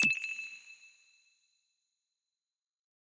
generic-hover.wav